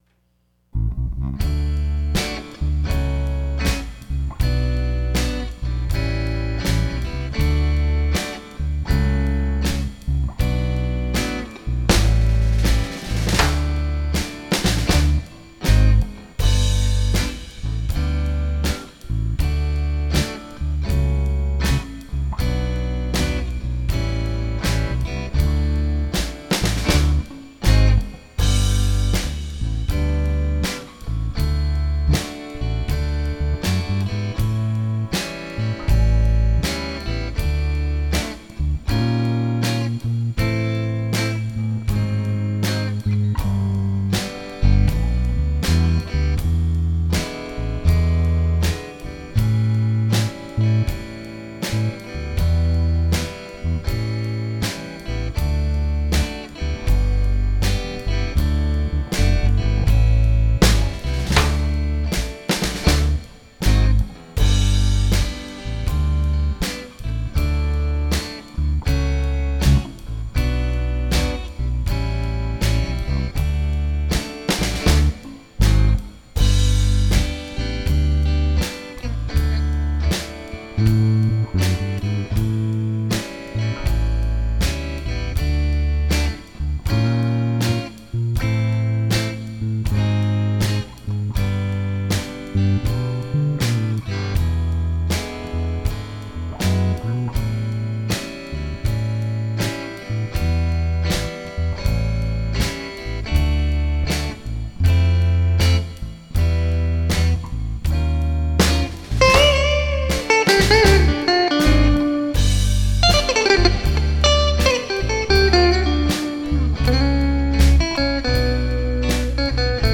Rhythm Track